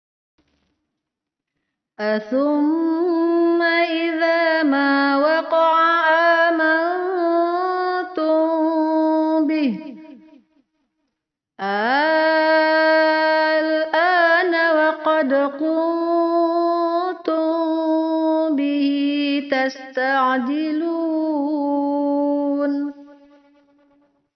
Panjang 6 harokat sama dengan 3x ayun suara.